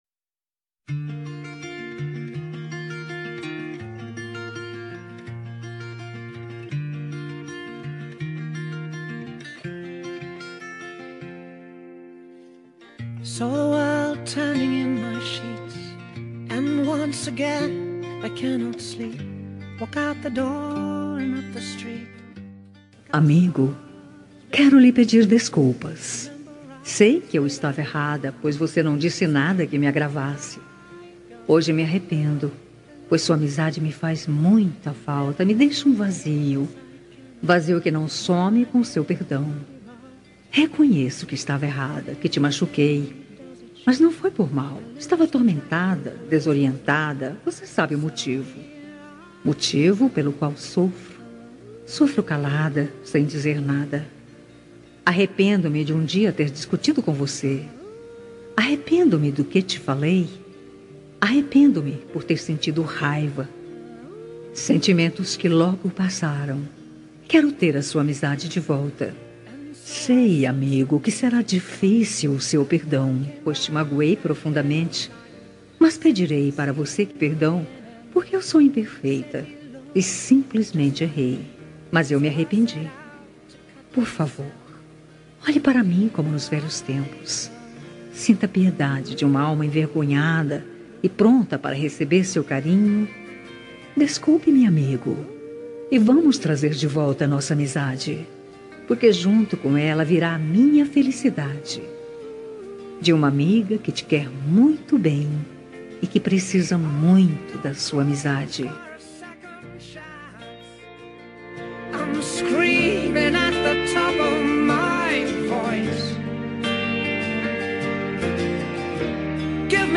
Reconciliação Amizade – Voz Feminina – Cód: 036796